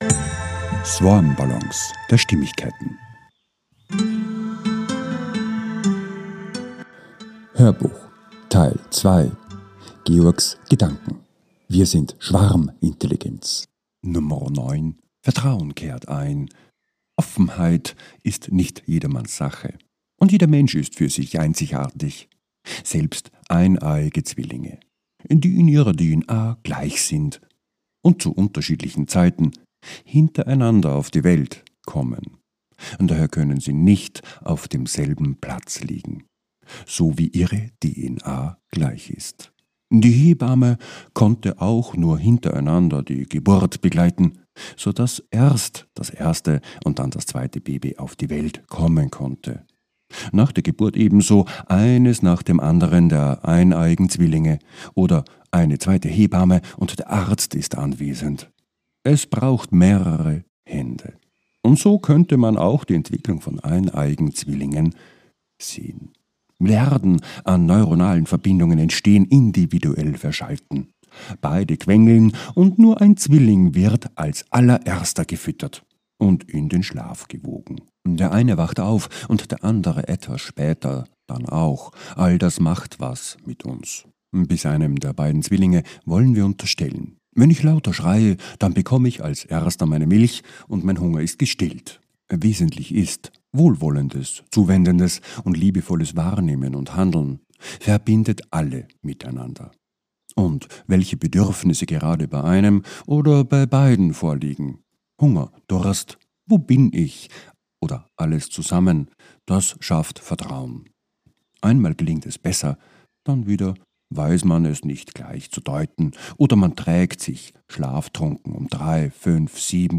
HÖRBUCH TEIL 2 - 09 - WIR SIND SCHWARMINTELLIGENZ 2 - VERSTEHEN kehrt ein ~ SwarmBallons A-Z der Stimmigkeit Podcast